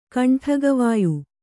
♪ kaṇṭhagatavāyu